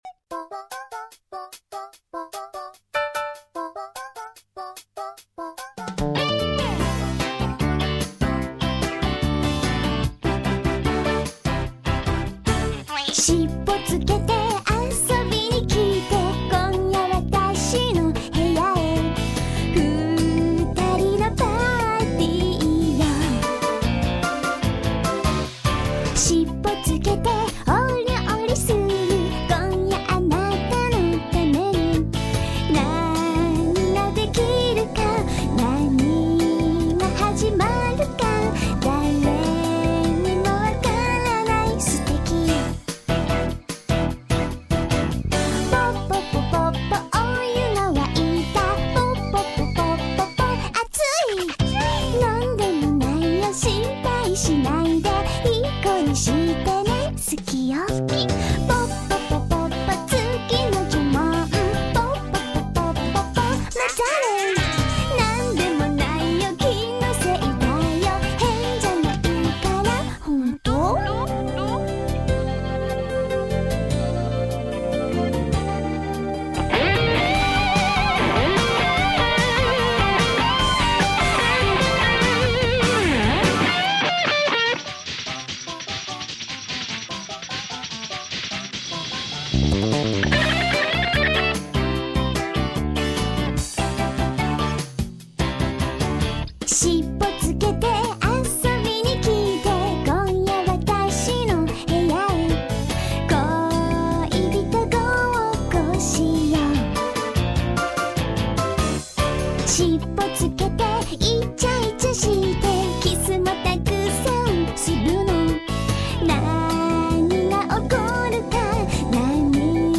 Забавная песенка